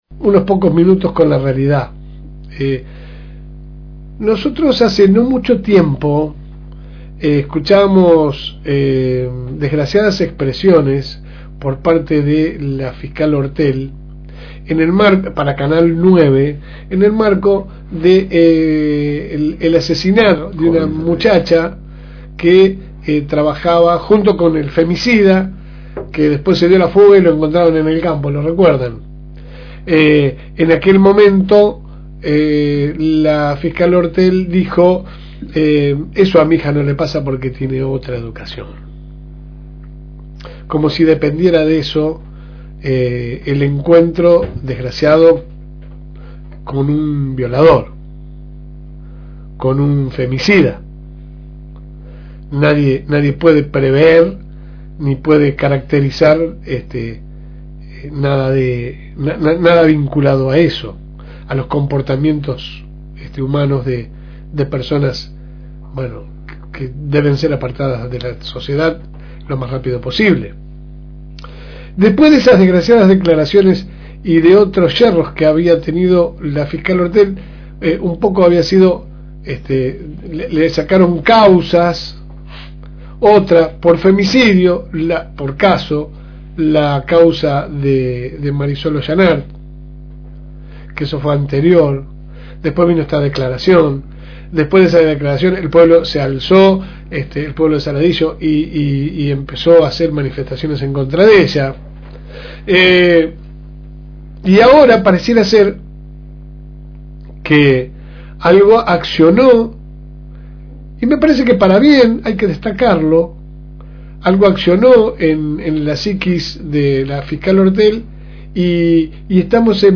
Que sale por el aire de la FM 102.9 de lunes a viernes de 10 a 12 HS